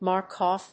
発音記号
• / ˈmɑˌrkɔf(米国英語)
• / ˈmɑ:ˌrkɔ:f(英国英語)